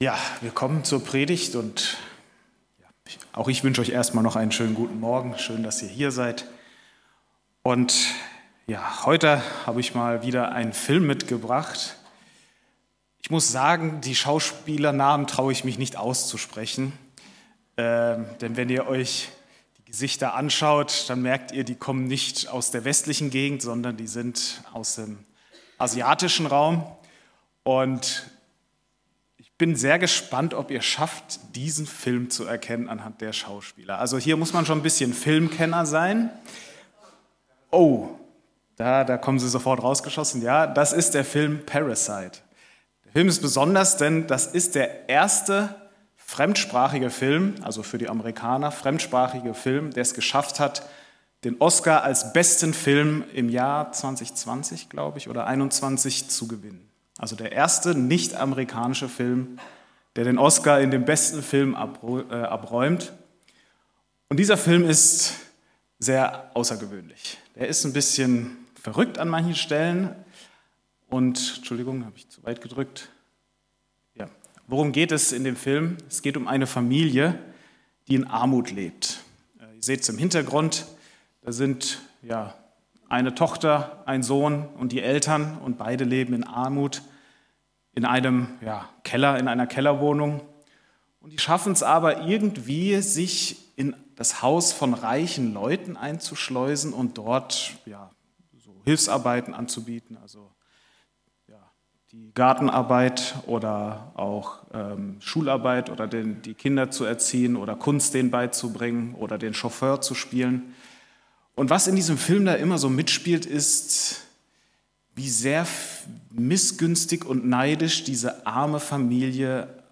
Es gibt ein Sprung in der Aufnahme, daher fehlen 10min.